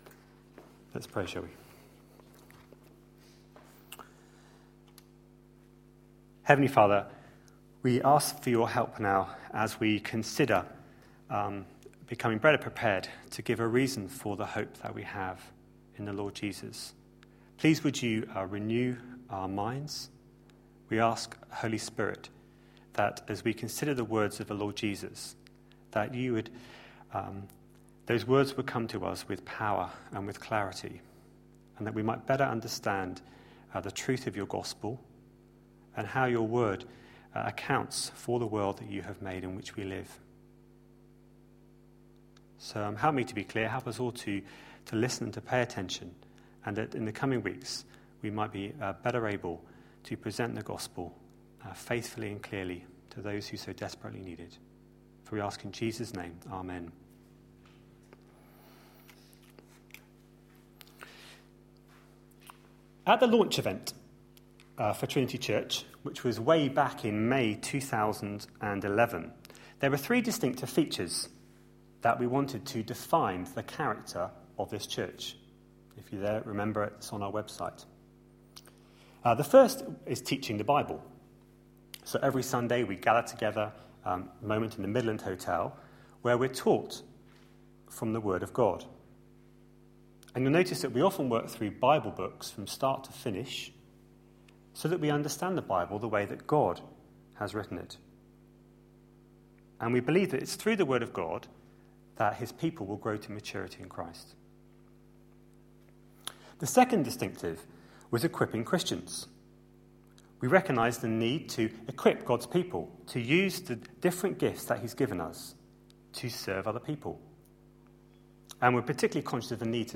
A sermon preached on 6th January, 2013, as part of our The gospel is the reason series.